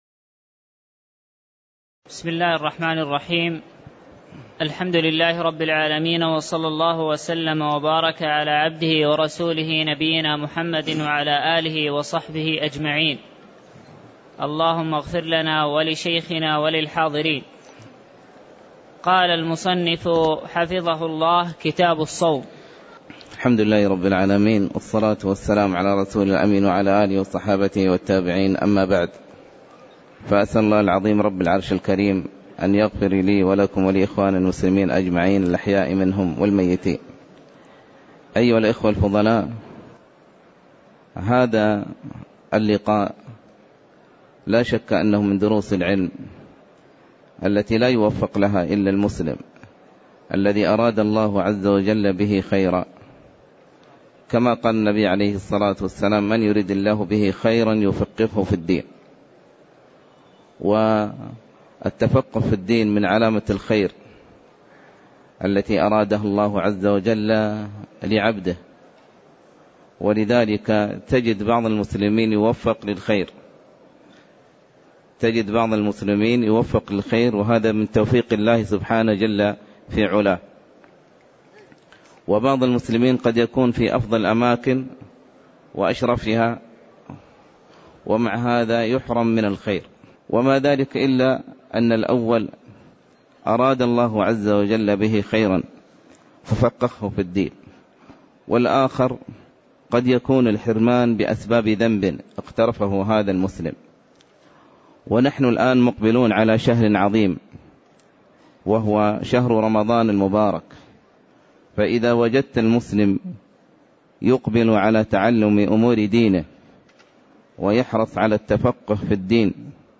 تاريخ النشر ١٨ شعبان ١٤٣٧ هـ المكان: المسجد النبوي الشيخ